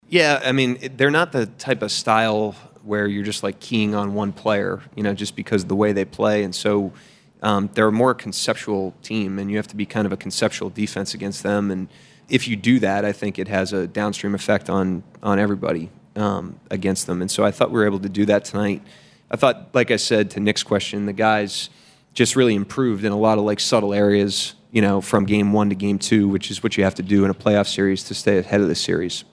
Thunder head coach Mark Daigneault talks about the improvement on that side of the ball against the Pacers
Daigneault on Thunder Defense 6-10.mp3